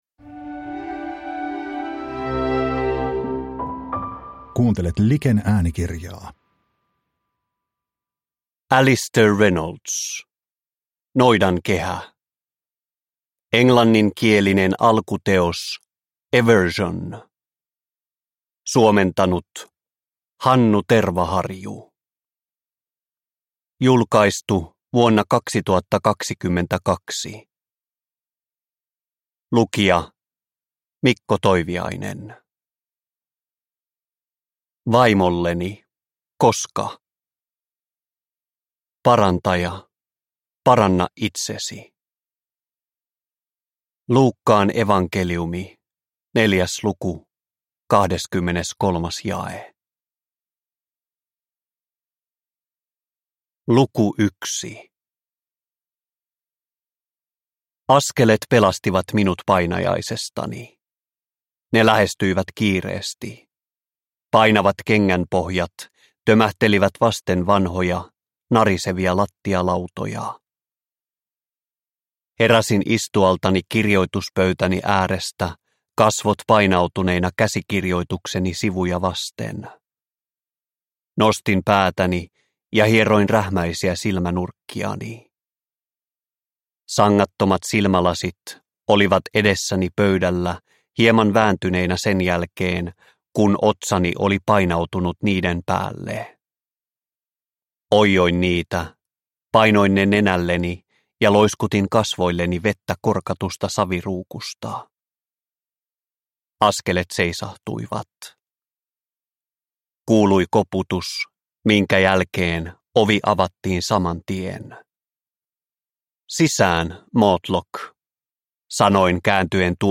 Noidankehä – Ljudbok – Laddas ner